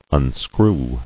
[un·screw]